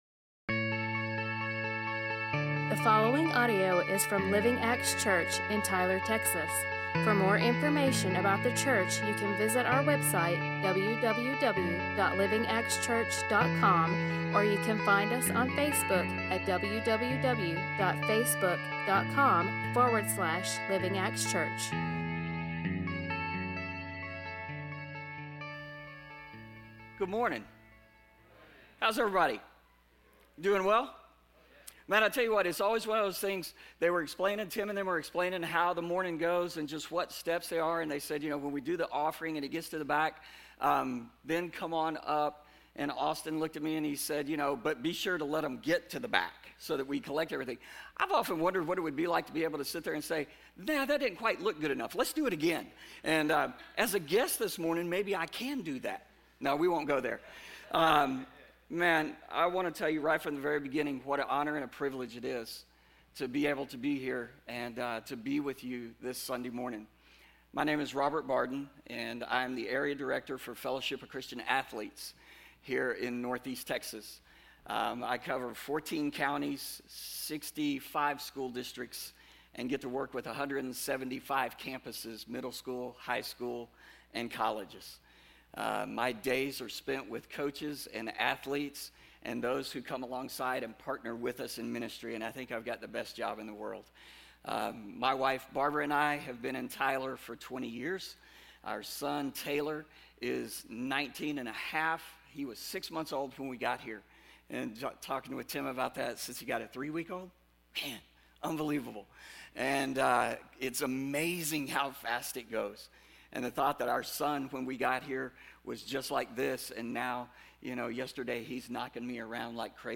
A message from the series "Knowing Our God."